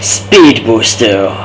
pusuperspeedvoice.wav